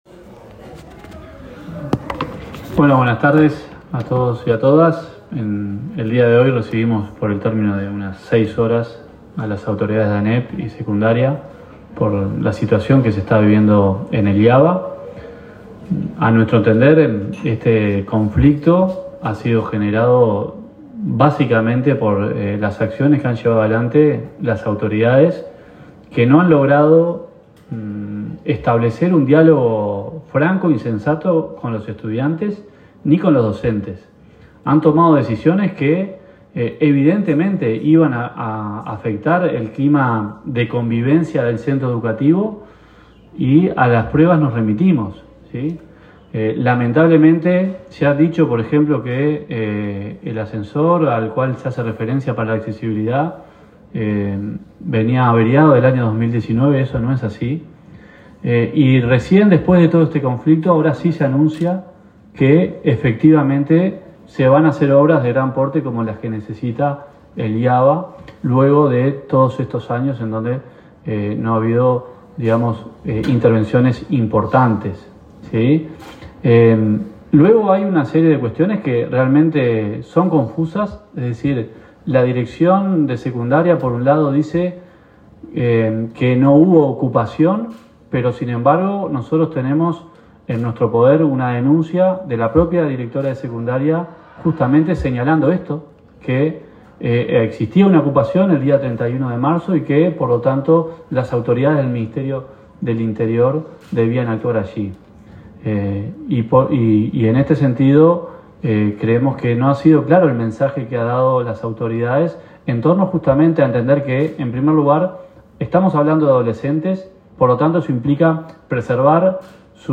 En declaraciones a la prensa, Liliam Kechichian y Sebastián Sabini sostuvieron que este conflicto ha sido generado por las autoridades de la educación que no han logrado establecer un diálogo franco y sensato con docentes y estudiantes.